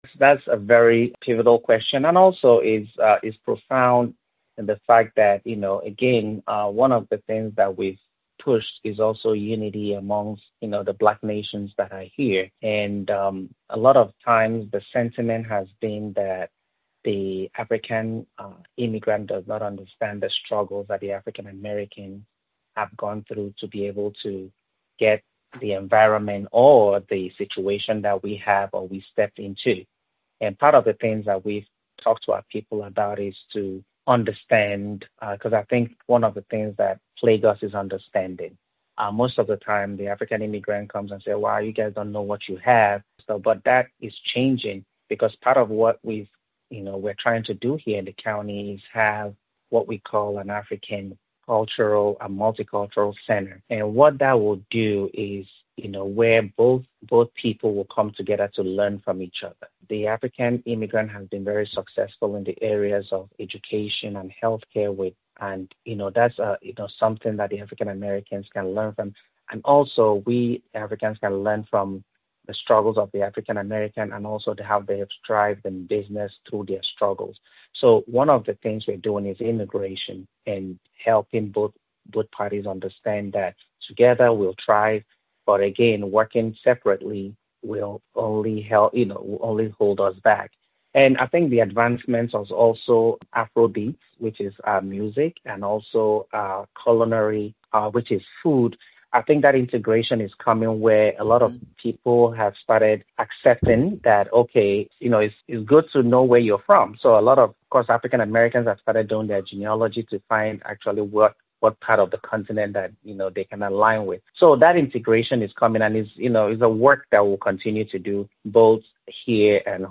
This diverse community is becoming an increasingly critical constituency in American elections, asserting both their economic and political power. In part 2 of his conversation